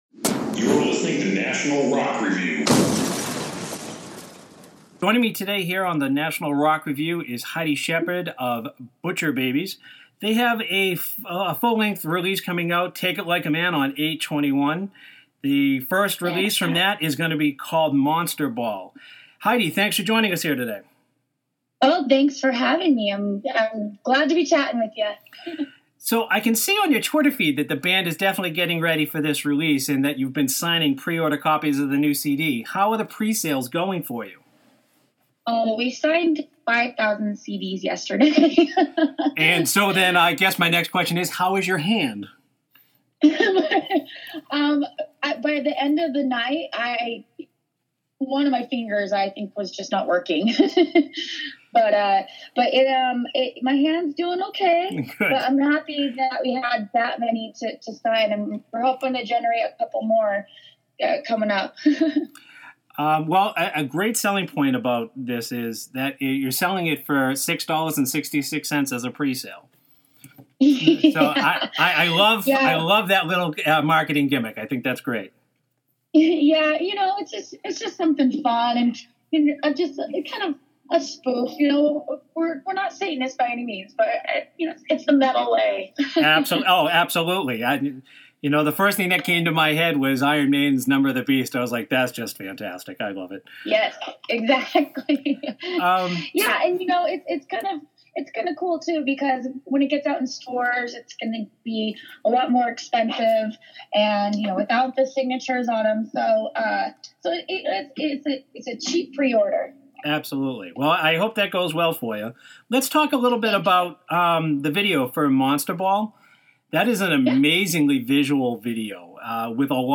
Heidi Shepard from the unstoppable band Butcher Babies sat down for a few moments to speak to National Rock Review.